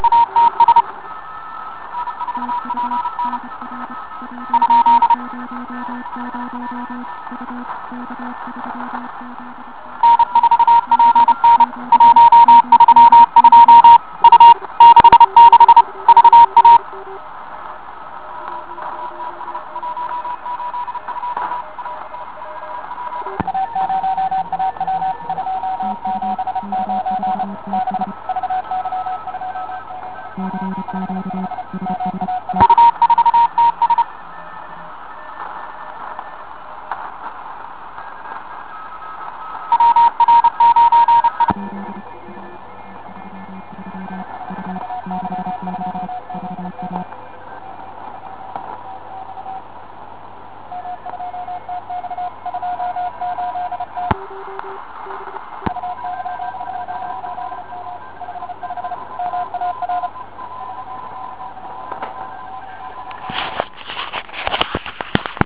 Trénujme pro závody CW
Přijímač RM40. je to přímosměšující celkem neodolný přijímač.
Ještě upozorňuji, že offset je zde nastaven asi na 900 Hz.
DX_RM40.wav